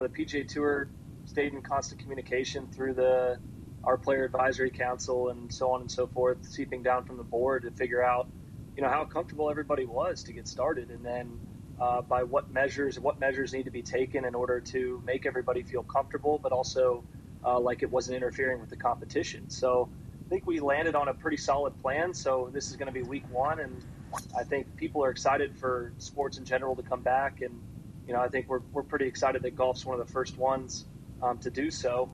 In a recent interview, Spieth described just how exciting it is to finally be back. He also recognizes that the officials did a good job on laying down a plan that would ensure a safe return for everyone.